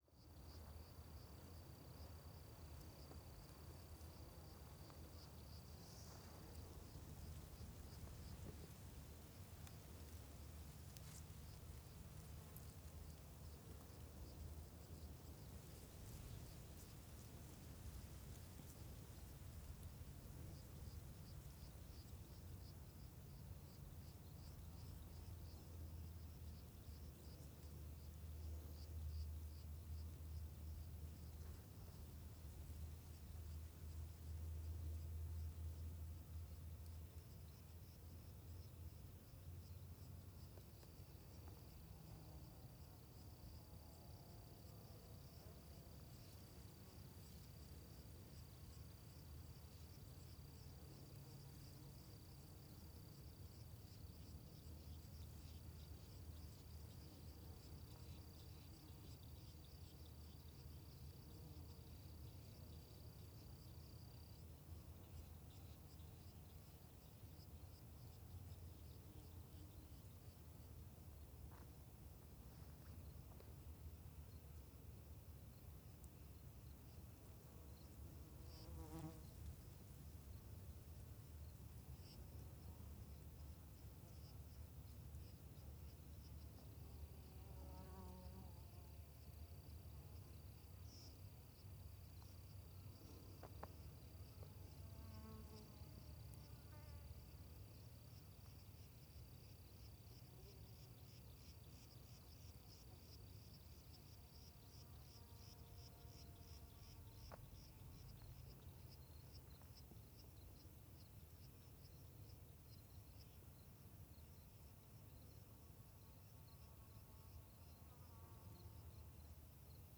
CSC-05-132-GL - Ambiencia em Campo Aberto da Trilha do Sertao Zen com Vento Suave.wav